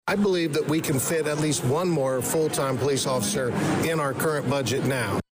Full Crowd Comes to St. Mary’s Church for Westville Candidates Forum